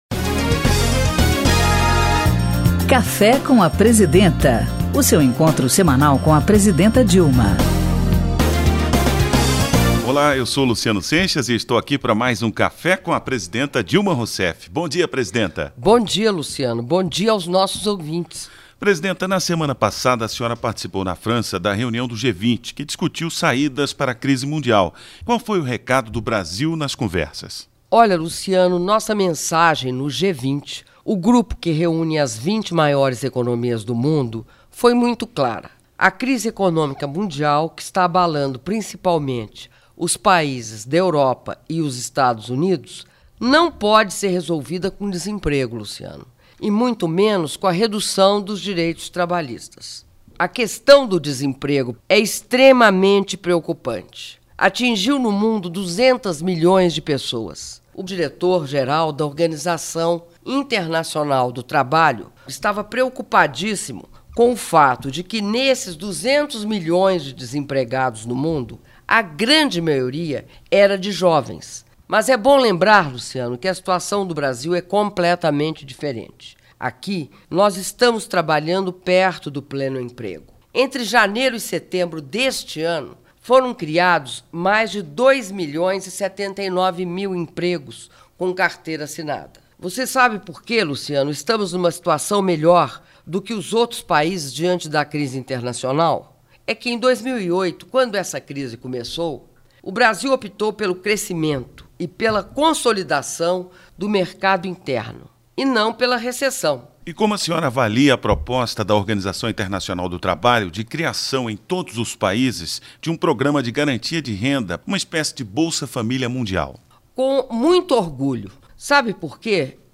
audio da entrevista concedida pela presidenta da republica dilma rousseff no programa de radio cafe com a presidenta 06min07s